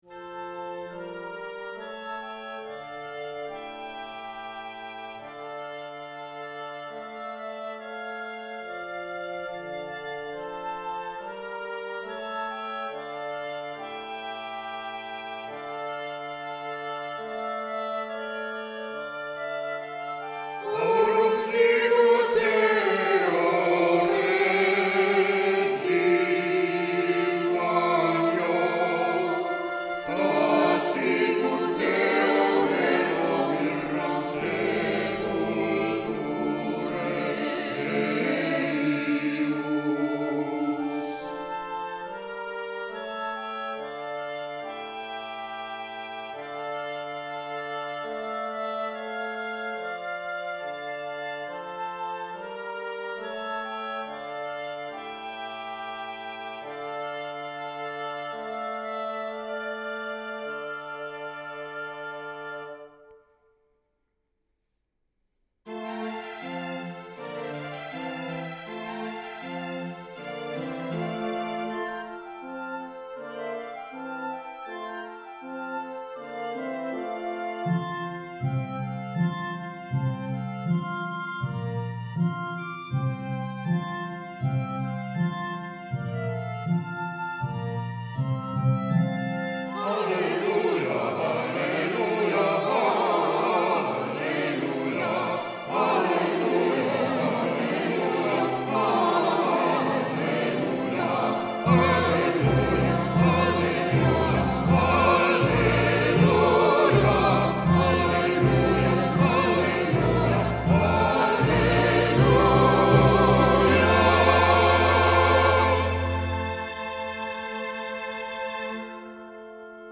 3-osainen sinfoninen teos